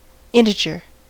integer: Wikimedia Commons US English Pronunciations
En-us-integer.WAV